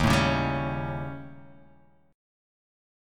D#7b5 chord